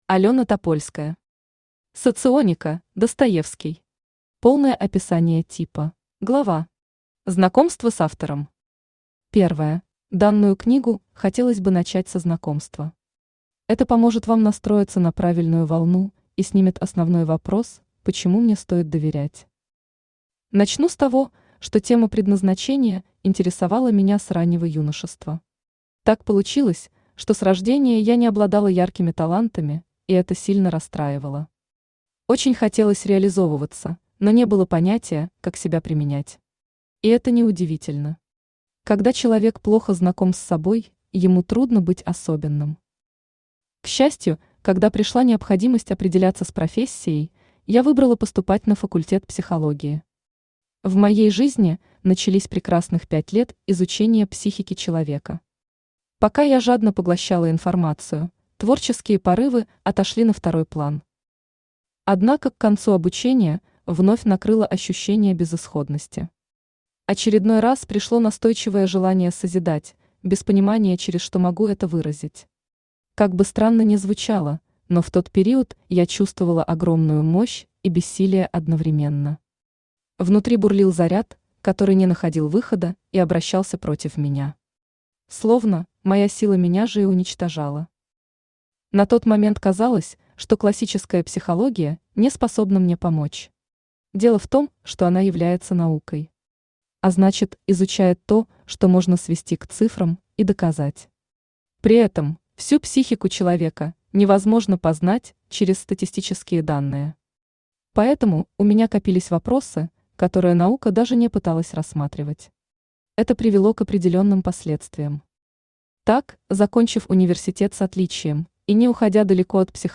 Аудиокнига Соционика: «Достоевский». Полное описание типа | Библиотека аудиокниг
Читает аудиокнигу Авточтец ЛитРес.